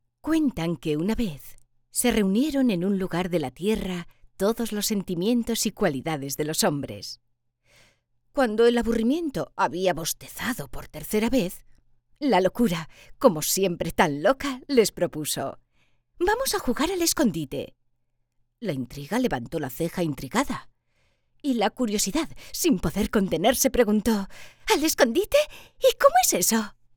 Locutora y actriz de doblaje.
Sprechprobe: Sonstiges (Muttersprache):
CUENTO CASTELLANO 1B.mp3